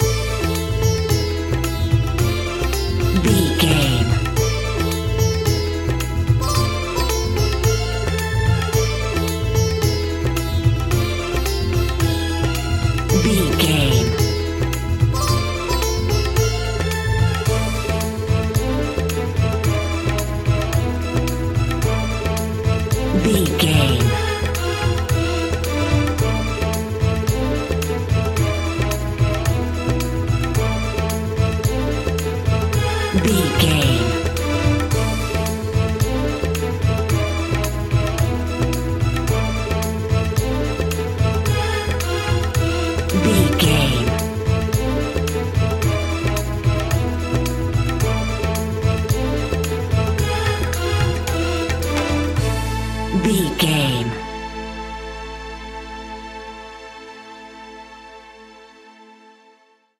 Aeolian/Minor
percussion
kora
kalimba
marimba